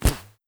archer_bow_release.wav